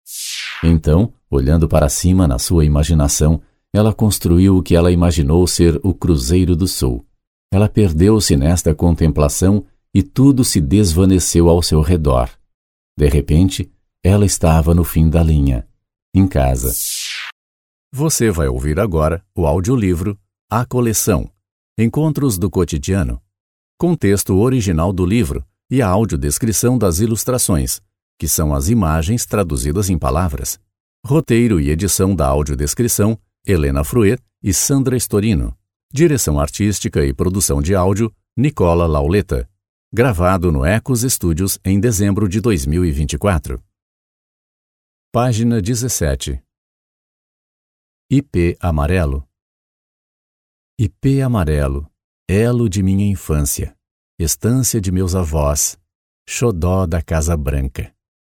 Audiolibros
Mi voz es profunda, amigable, natural y conversacional.
Trabajo desde mi propio estudio profesionalmente equipado y con tratamiento acústico.
Voz profunda, natural y conversacional, suave y clásica